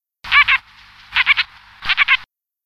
Philomachus pugnax